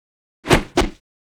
快速的两下拳-YS070510.wav
通用动作/01人物/03武术动作类/空拳打斗/快速的两下拳-YS070510.wav
• 声道 單聲道 (1ch)